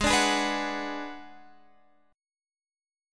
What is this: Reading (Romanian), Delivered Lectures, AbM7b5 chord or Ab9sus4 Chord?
AbM7b5 chord